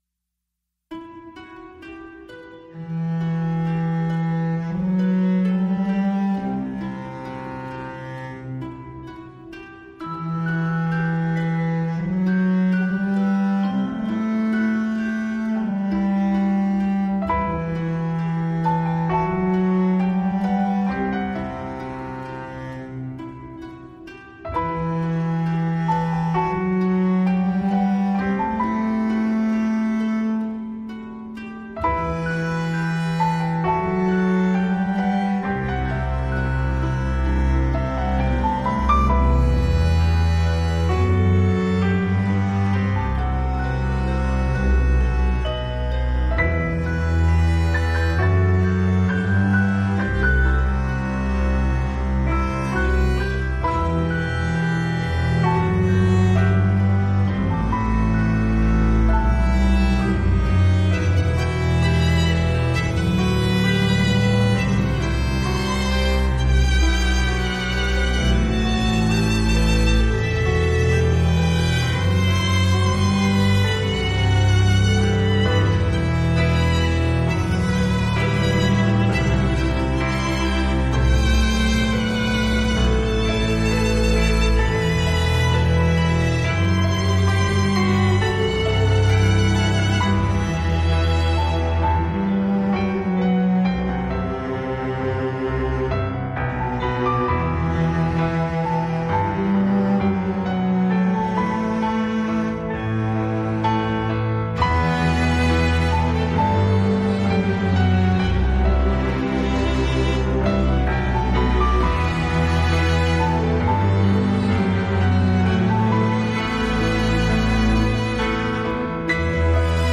Atmosphärische Musik zum Spielen mp3